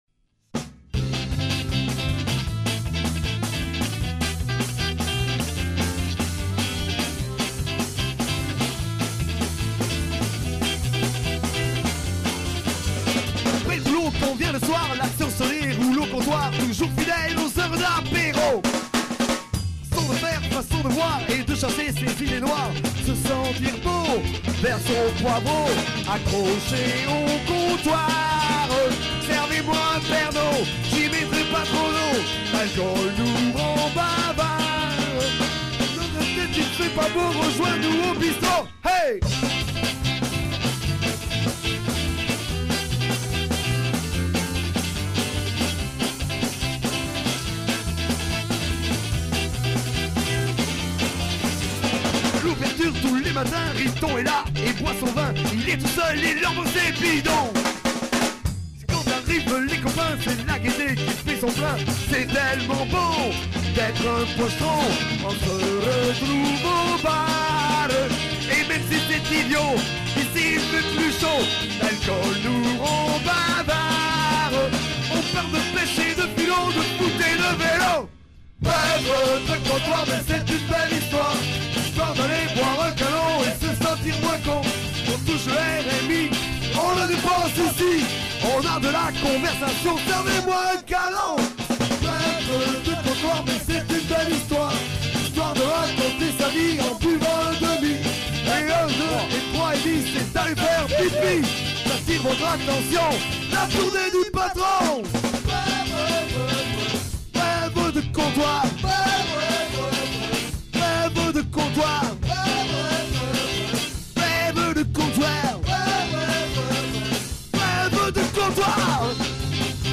Studio